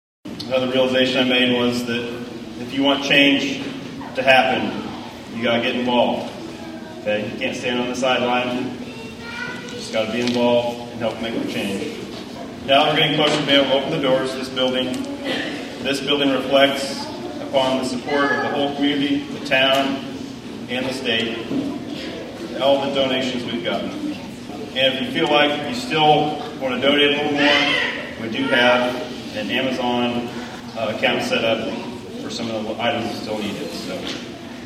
The communities support and involvement in this project was echoed by all who spoke at the ceremony.